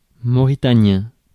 Ääntäminen
Ääntäminen France: IPA: [mo.ʁi.ta.njɛ̃] Haettu sana löytyi näillä lähdekielillä: ranska Käännös Adjektiivit 1. mauretanisch Suku: m .